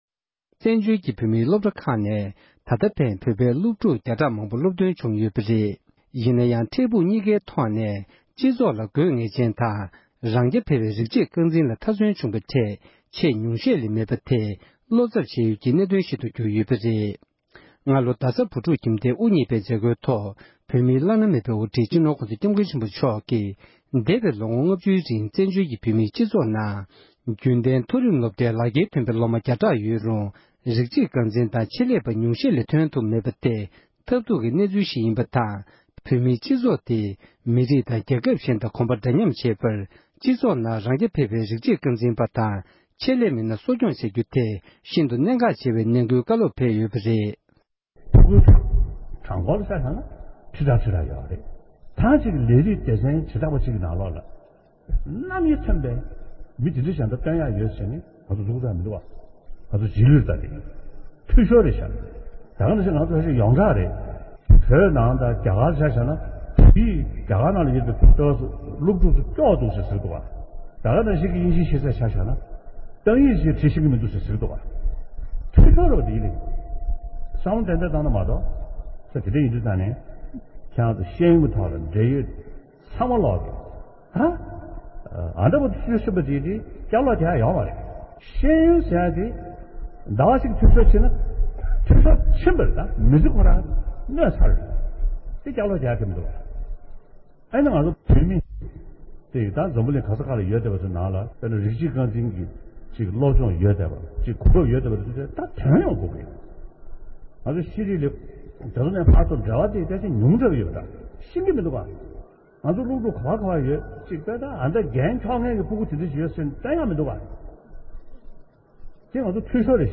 གནས་འདྲི་ཞུས་པའི་ལེའུ་དང་པོ་དེར་གསན་རོགས་ཞུ༎